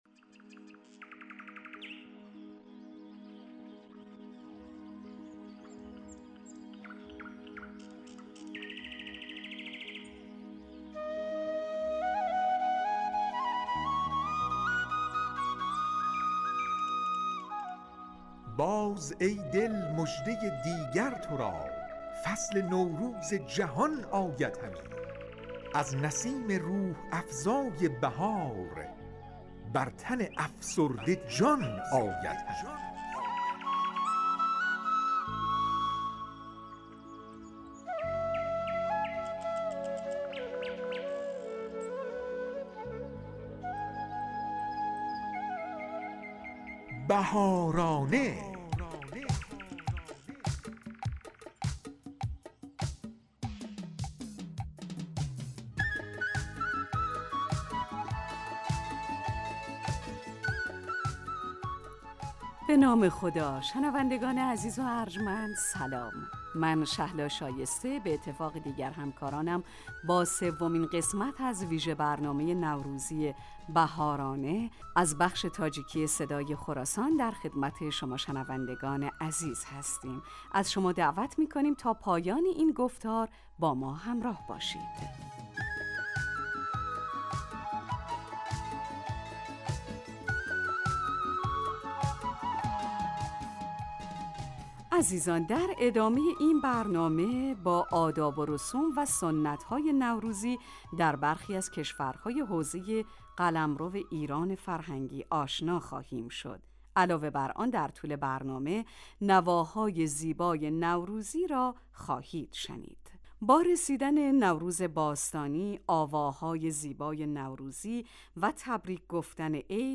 "بهارانه" ویژه برنامه نوروزی رادیو تاجیکی صدای خراسان است که به مناسبت ایام نوروز در این رادیو به مدت 30 دقیقه تهیه و پخش می شود.